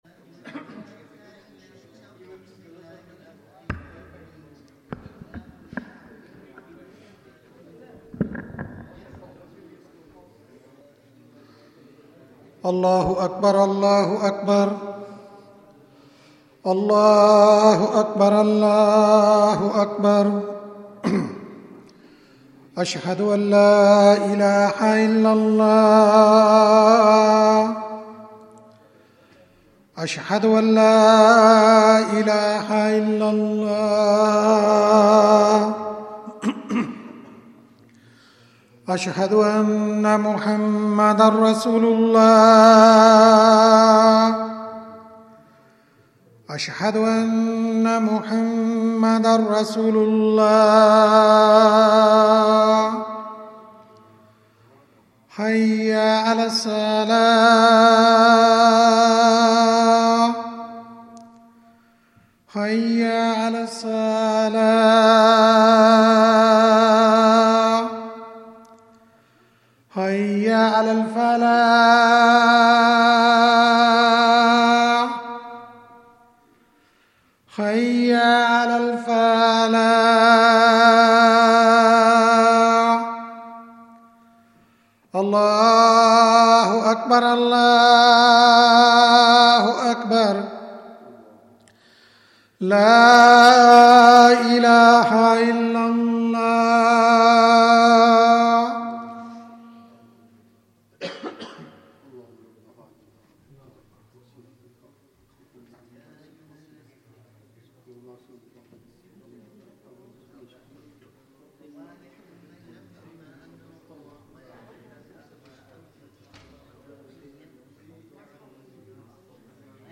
Salat al-Taraweeh